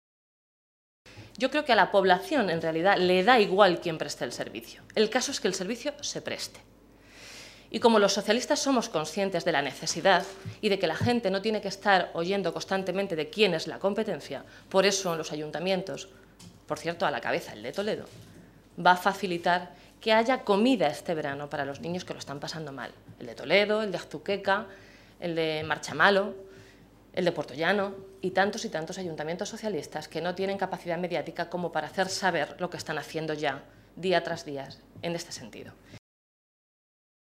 Blanca Fernández, portavoz de Educación del Grupo Parlamentario Socialista
Cortes de audio de la rueda de prensa